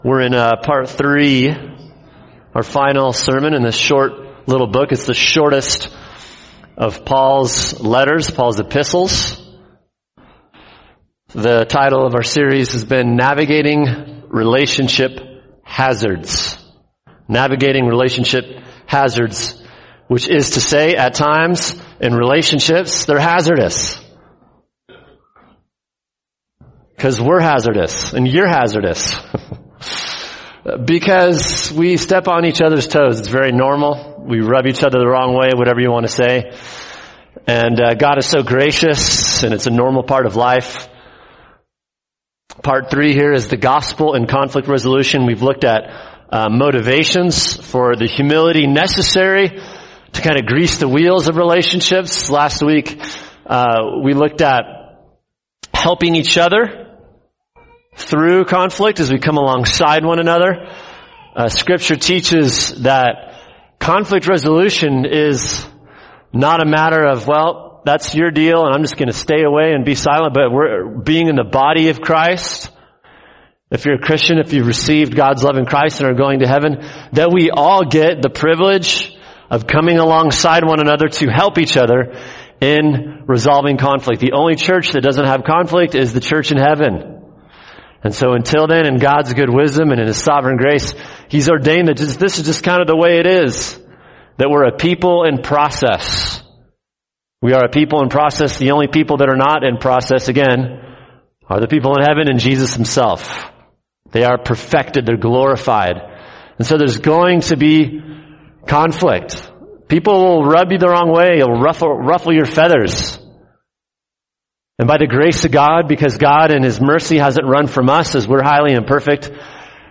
[sermon] Philemon 17-25 – Navigating Relationship Hazards, Part 3 | Cornerstone Church - Jackson Hole